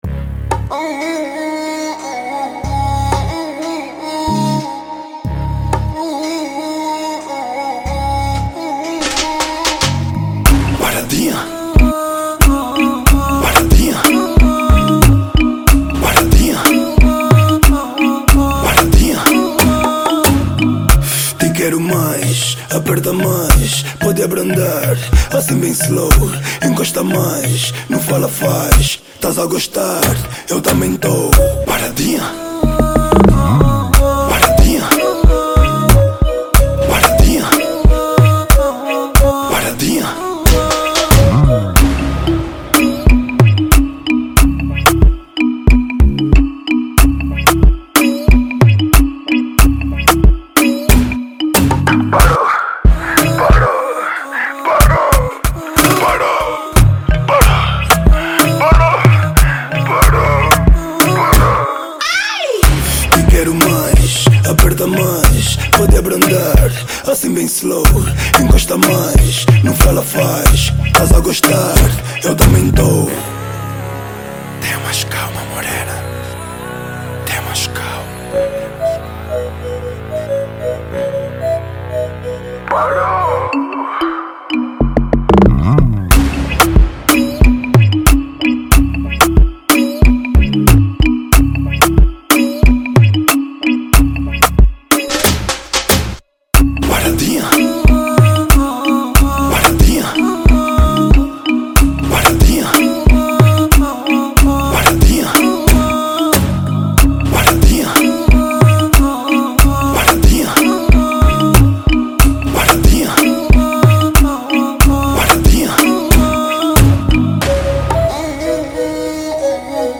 Genero: Tarraxinha